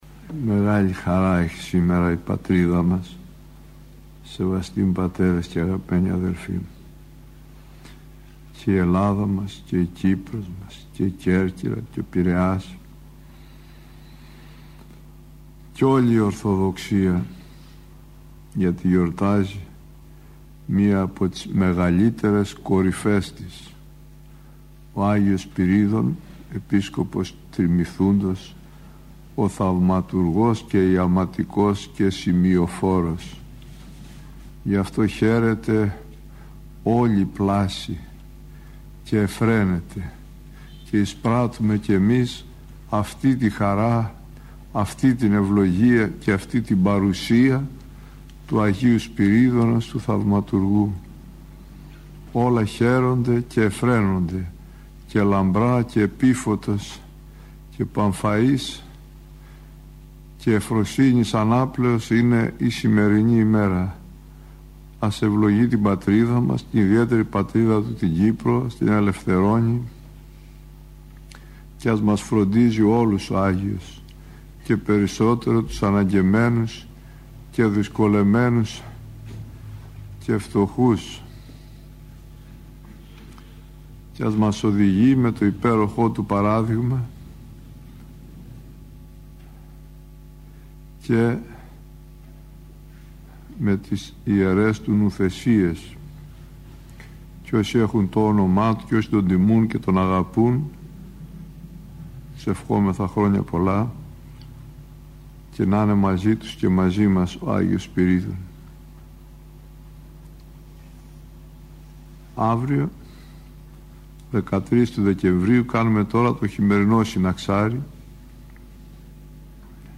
Η εν λόγω ομιλία αναμεταδόθηκε από τον ραδιοσταθμό της Πειραϊκής Εκκλησίας.